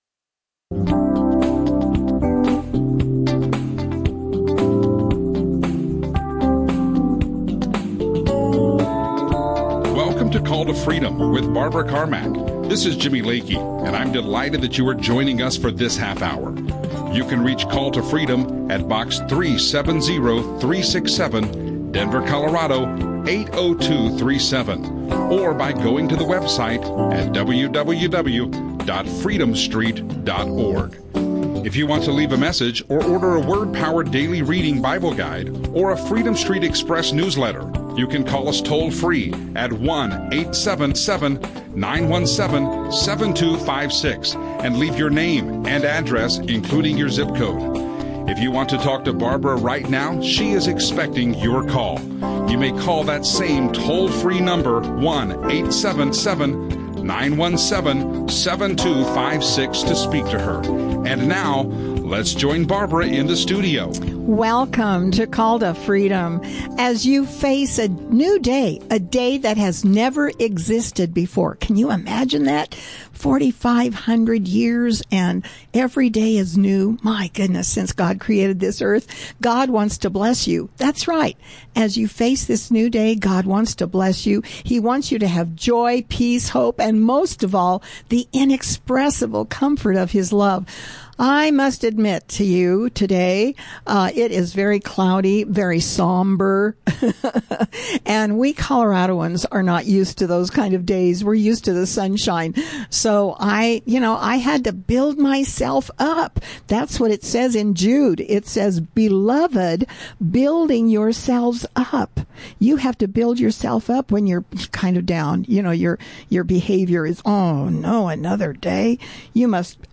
Christian talk
Hamas Ilhan Omar KLTT Ministry radio show righteousness talk radio